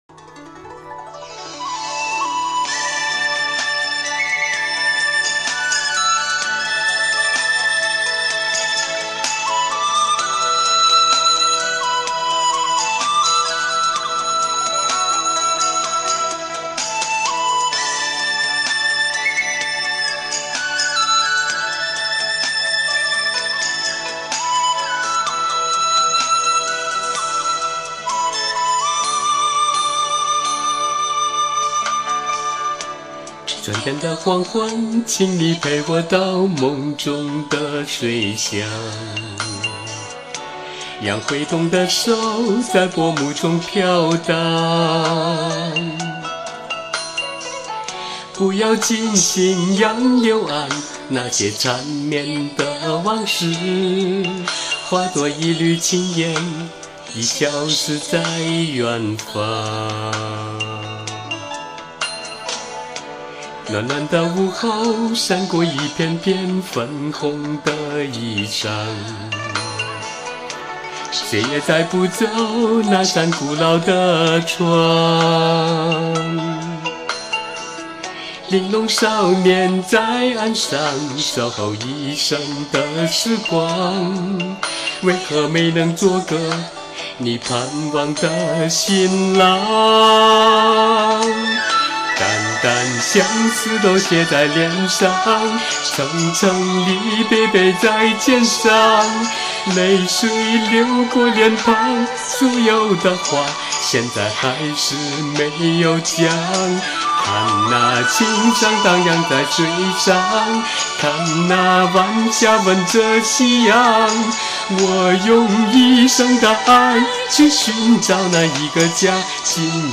这歌不太适合男生唱